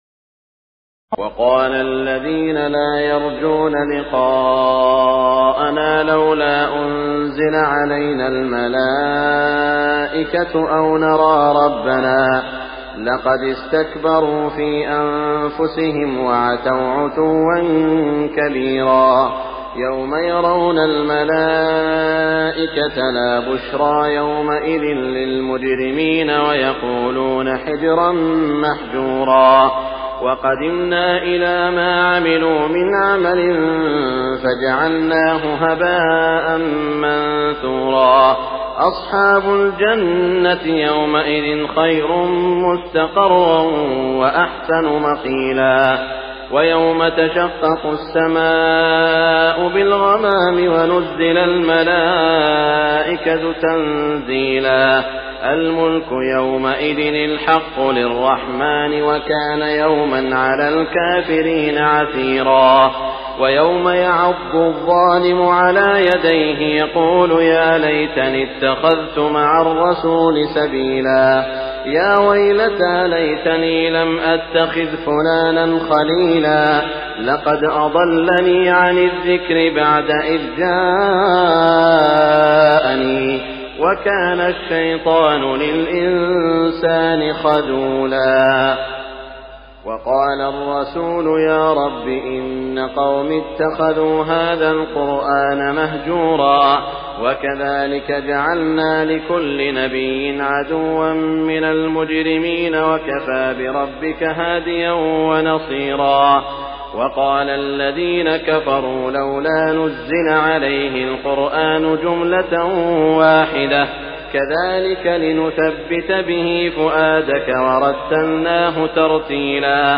تراويح الليلة الثامنة عشر رمضان 1418هـ من سورتي الفرقان (21-77) و الشعراء (1-104) Taraweeh 18 st night Ramadan 1418H from Surah Al-Furqaan and Ash-Shu'araa > تراويح الحرم المكي عام 1418 🕋 > التراويح - تلاوات الحرمين